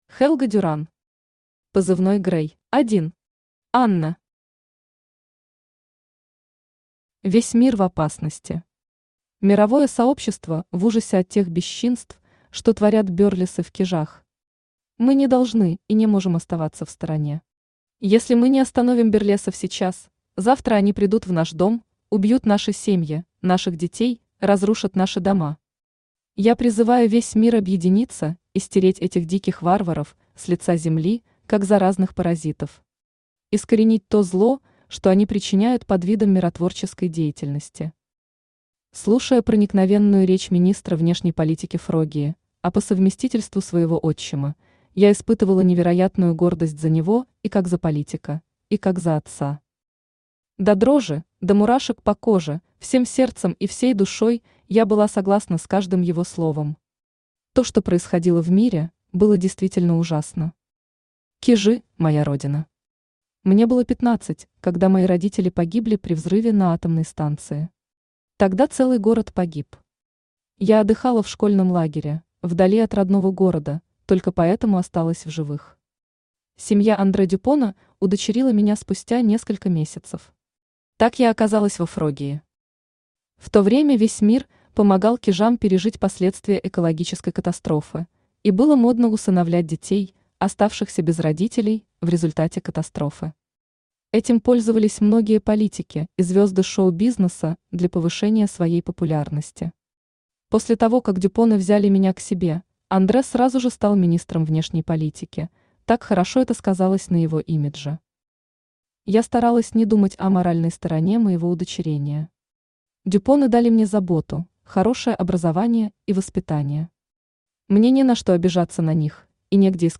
Aудиокнига Позывной «Грэй» Автор Helga Duran Читает аудиокнигу Авточтец ЛитРес.